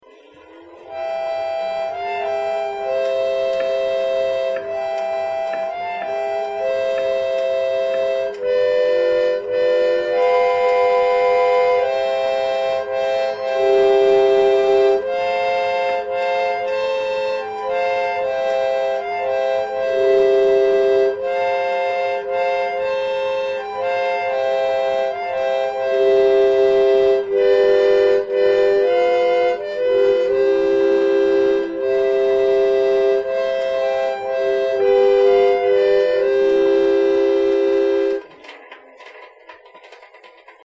Mais il s'agit avant tout, et c'est cela qui m'intéresse, d'un véritable orgue de barbarie, ou plus exactement d'une organette, comme ils disent là-bas, à anches et rouleaux.
Le principe de fonctionnement est tout simple : Une turbine actionnée par une manivelle, envoie de l'air derrière une sorte d'harmonica dont les trous sont directement contrôlés par les perforations d'un rouleau de papier.
La gamme commence au Mi3 (midi 64) et comporte 16 notes sur des rouleaux de 4.25" Et puis... figurez-vous que l'on trouve  encore des rouleaux.
On entend un peu le ronronnement de la turbine, mais si peu.
bulletLe niveau sonore de l'instrument est juste comme il faut, et le couvercle rabattu fait office de sourdine au besoin.